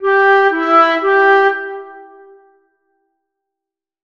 SOL-MI-SOL.wav